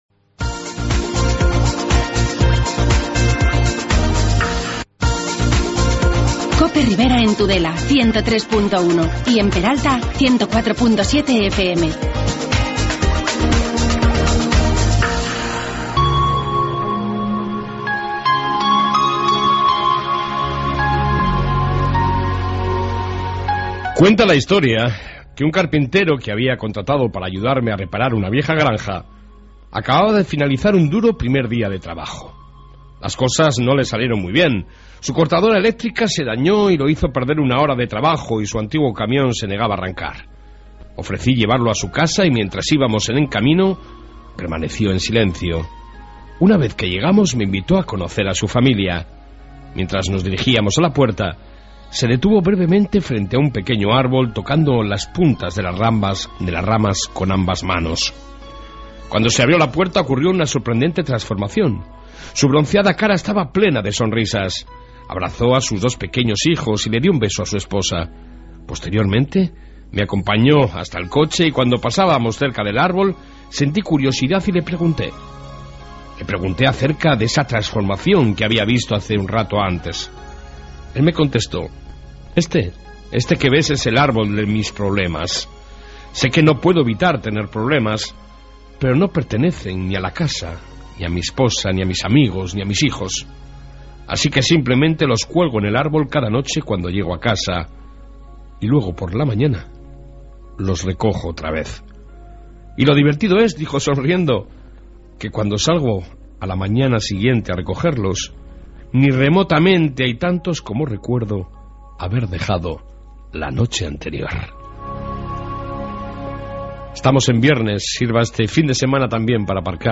AUDIO: iNFORMATIVO Y ENTREVISTA SOBRE EL BARROCO EN ESSTA 1 PARTE